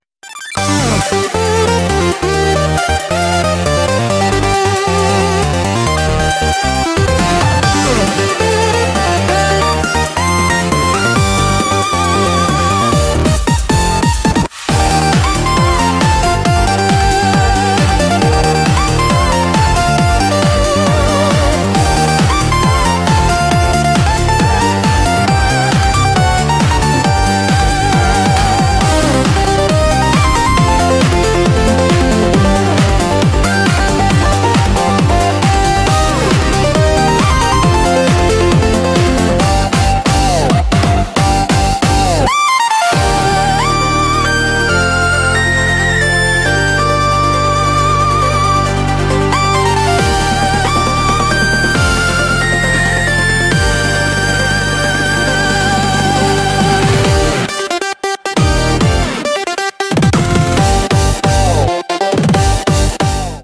8-Bit
Each sample is engineered to capture the iconic tone and character of retro gaming — from bleeps and bloops to power-up cues and effects that instantly evoke classic gameplay.
• 50 authentic 8-bit SFX samples